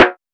SNARE.96.NEPT.wav